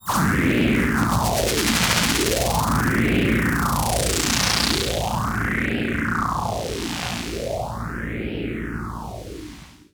Roland E Noises
Roland E Noise 13.wav